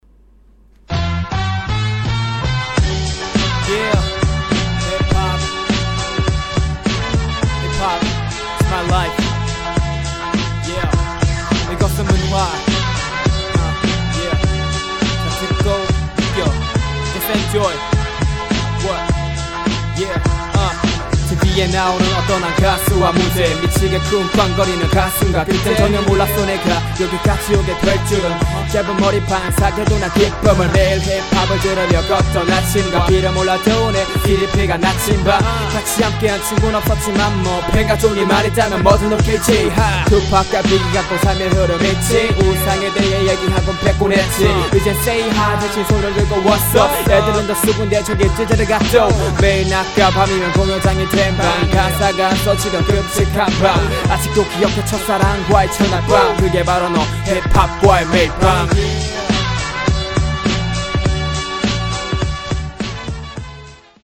• [REMIX.]
아우 발음이 계속 세서 미치겠네요 ㅠㅠ
발음 새는 것.
그리고 2번째 마디에서 3번째 마디 넘어가는 부분 좀 급했던거같고
'뭐든 오케이지' 여기 더블링 잘못친건가? 박자가 꼬이는거처럼들려